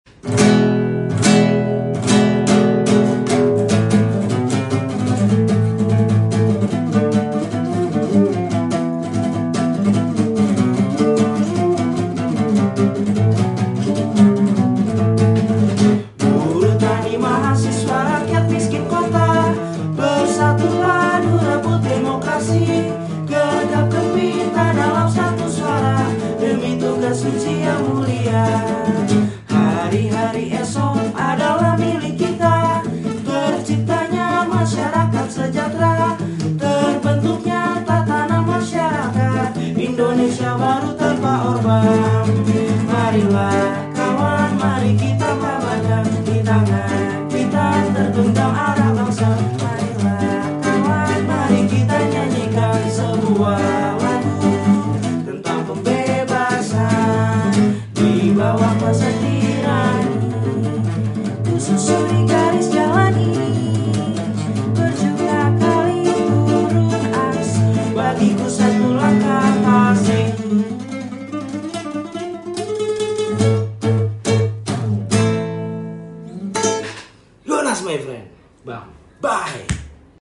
Versi Punk Rock. Demo DPR Agustus 2025.